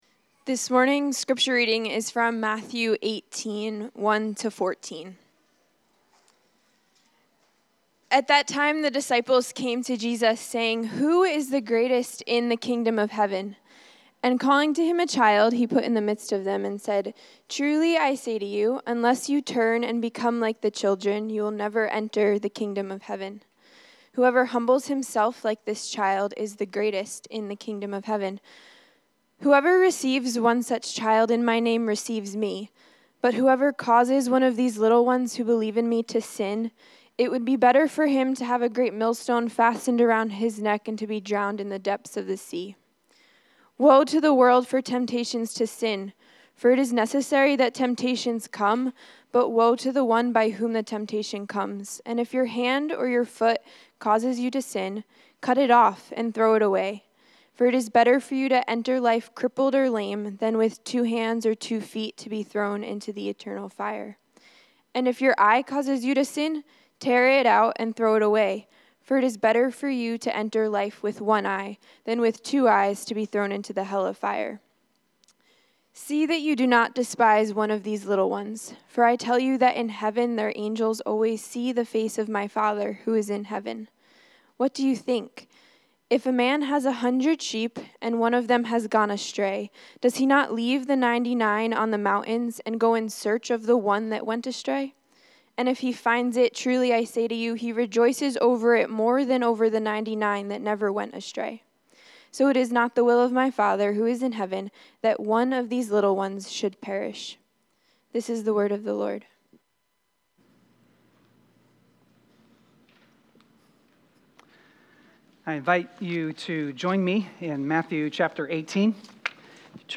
Sermons | Hope Christian Church